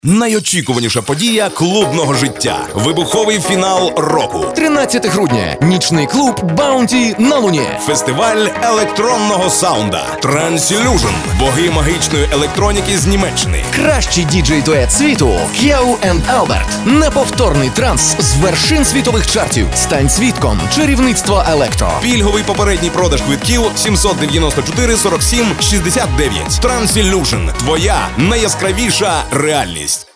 Рекламный ролик для радиостанций smile3.gif у нас под Are you fine :blush2: